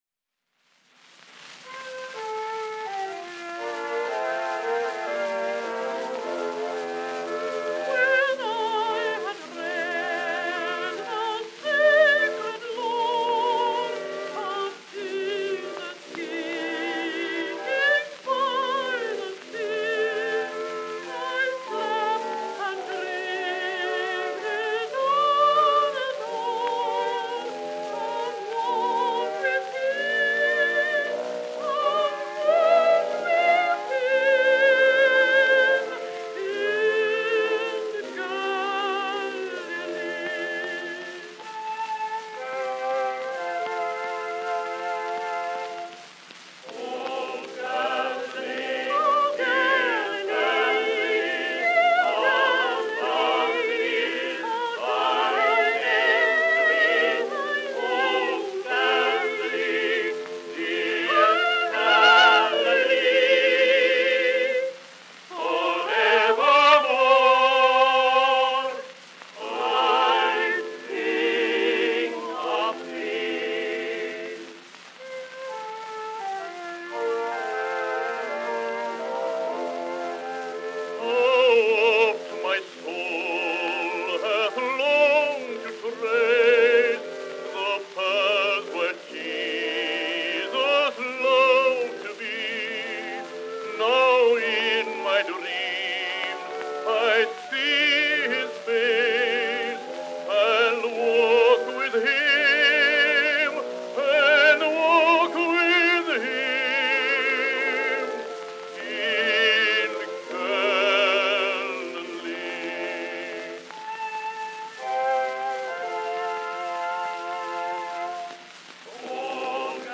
Sacred vocal duets with organ.